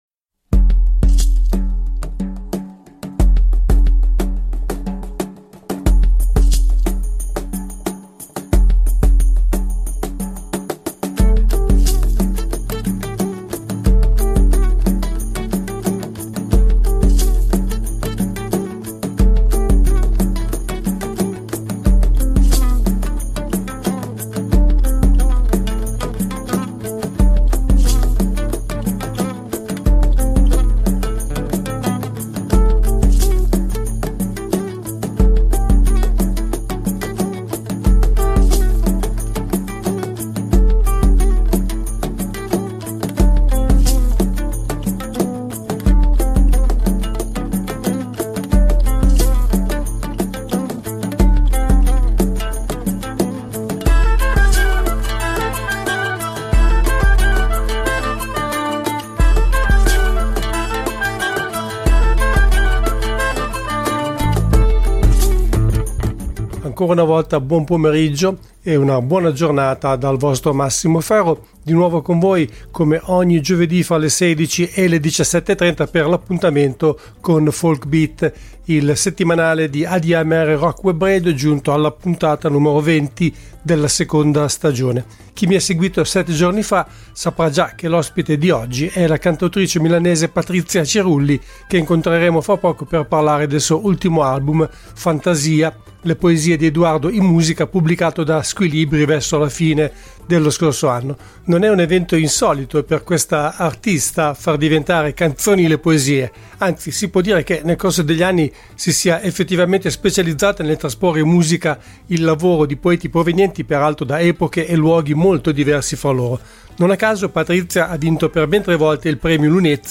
Ospite del programma al telefono